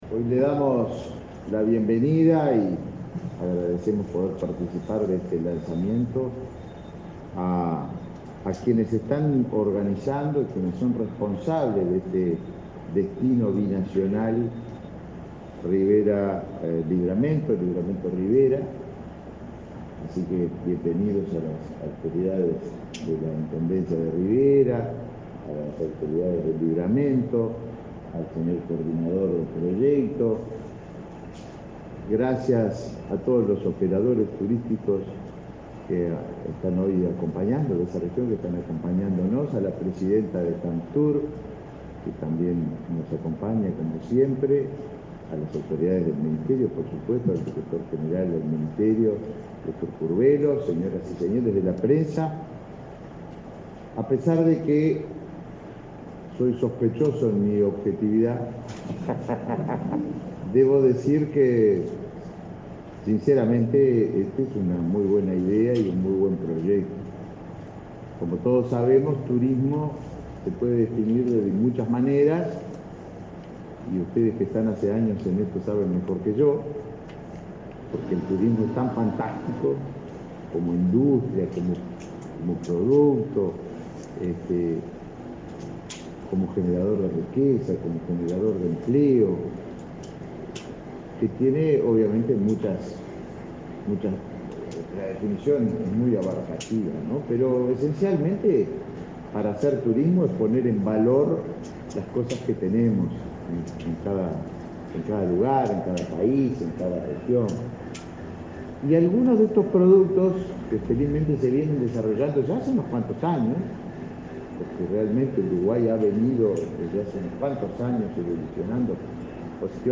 Palabras del ministro de Turismo, Tabaré Viera
El ministro de Turismo, Tabaré Viera, participó este miércoles 15 en la presentación del proyecto Destino Binacional, que busca potenciar una cultura